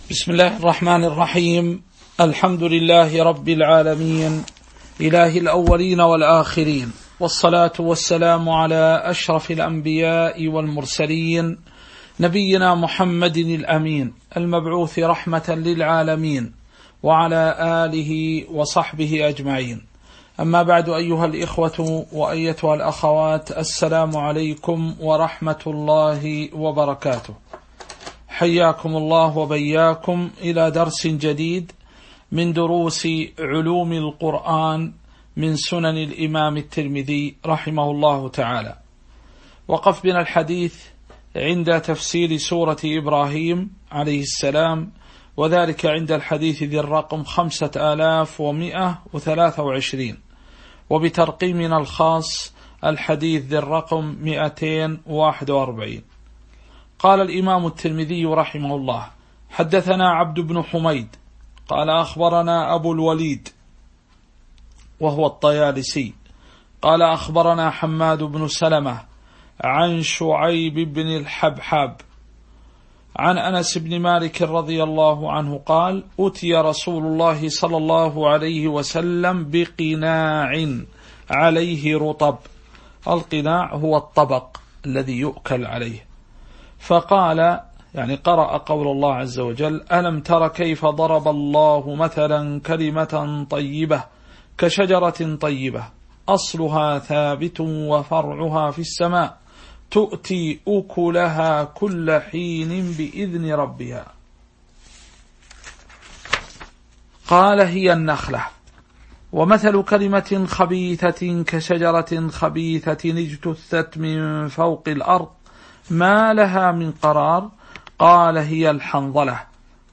تاريخ النشر ٩ جمادى الأولى ١٤٤٣ هـ المكان: المسجد النبوي الشيخ